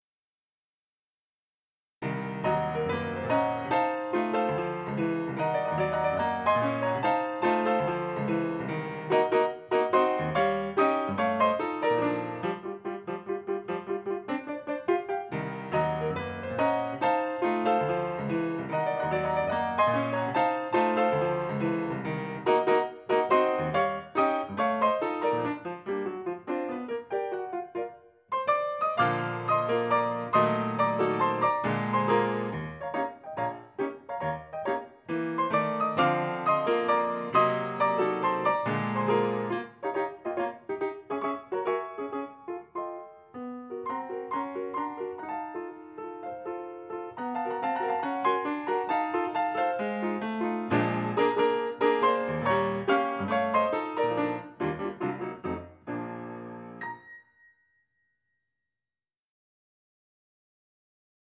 Complete piano music